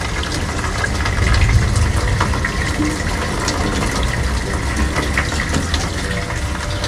rain01.wav